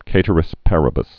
(kātər-ĭs părə-bəs)